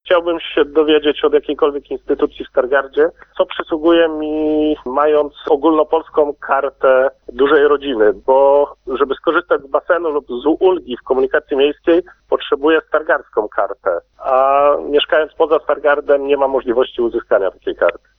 ogólnopolska karta dużej rodziny pytanie od słuchacza(1).mp3